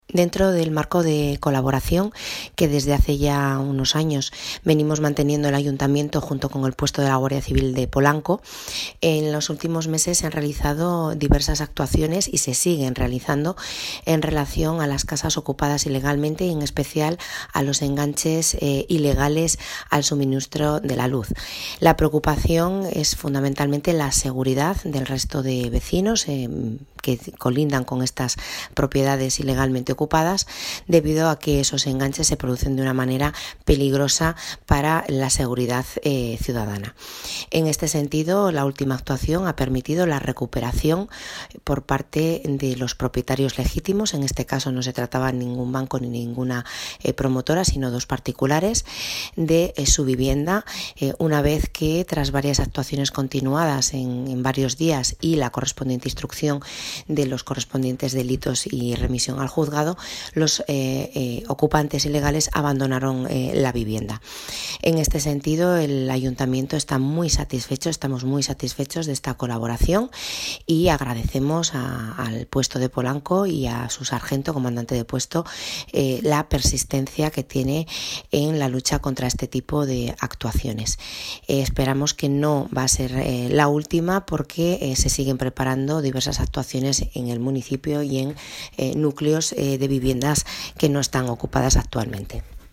Alcaldesa-sobre-ocupacion-de-viviendas-y-actuaciones-Guardia-Civil.mp3